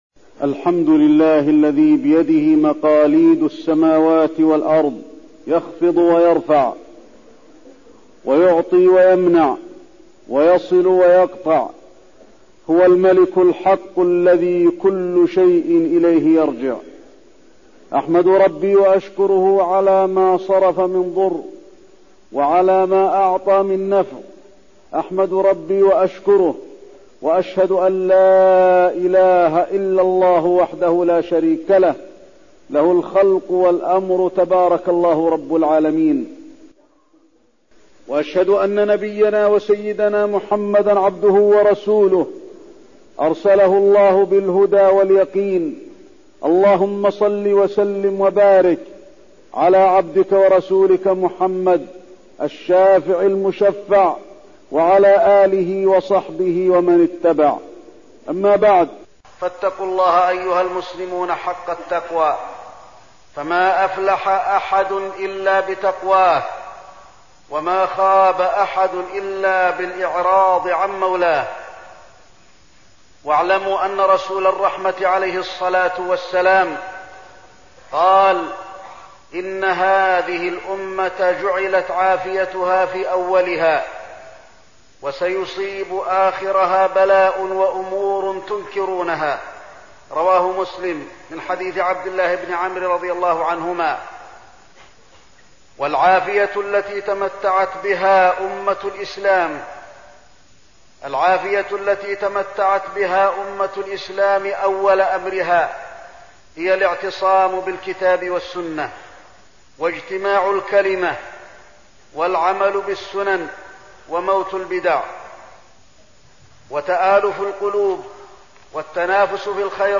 تاريخ النشر ٢٩ جمادى الأولى ١٤١٧ هـ المكان: المسجد النبوي الشيخ: فضيلة الشيخ د. علي بن عبدالرحمن الحذيفي فضيلة الشيخ د. علي بن عبدالرحمن الحذيفي المسجد الأقصى